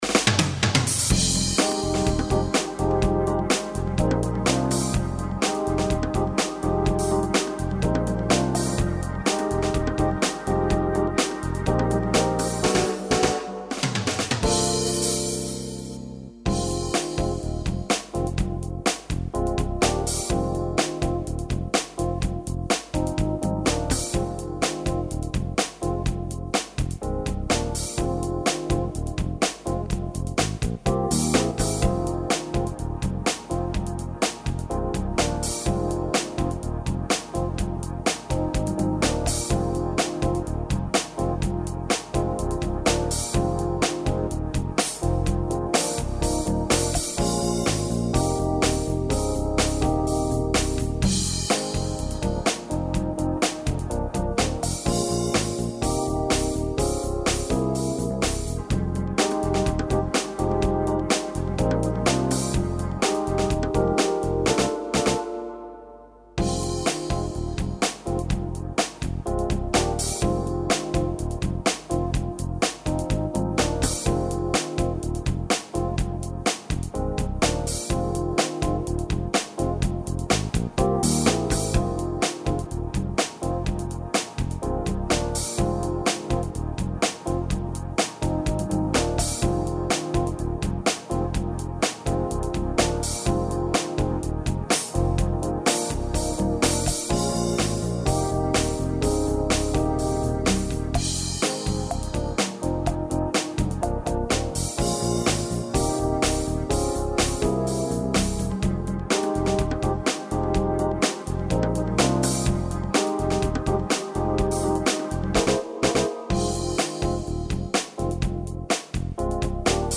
Base audio per studiare